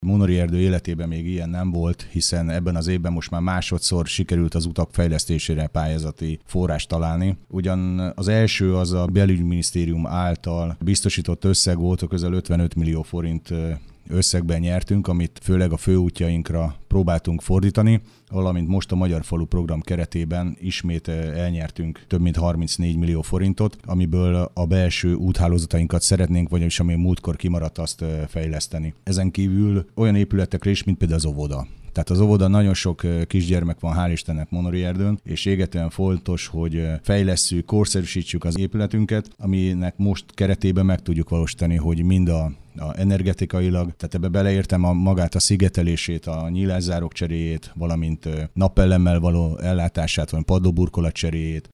Idén már másodjára nyert támogatást Monorierdő utak fejlesztésére. Emellett az óvoda korszerűsítésére is találtak forrást. Szente Béla polgármester foglalta össze a fejlesztéseket.